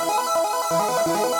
Index of /musicradar/shimmer-and-sparkle-samples/170bpm
SaS_Arp02_170-E.wav